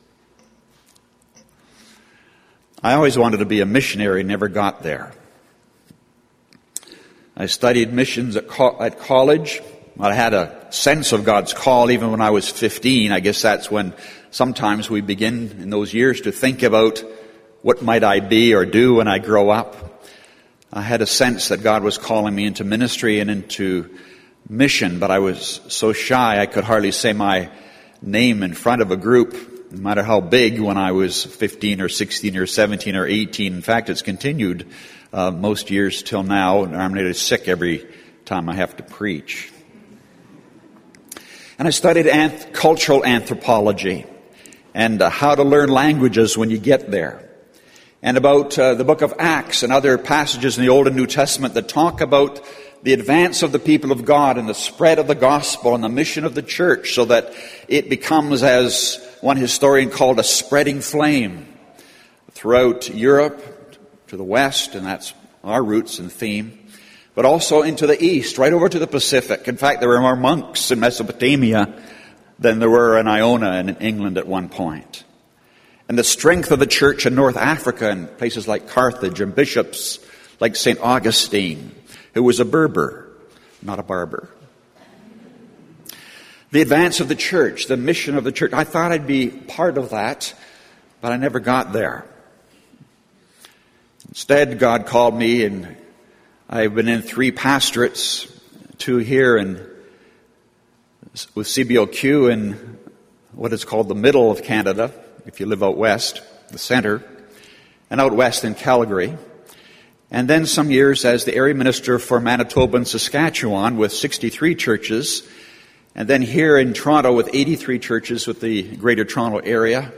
My Sense of Call to Mission Category: Think , About Church This short podcast is from the introduction to a sermon I preached at Kingsway Baptist Church, Etobicoke, Ontario, in September of 2014. The context was a 'series' of sermons, The Community of Love, about the breadth of the Gospel and the Church's privilege to share it.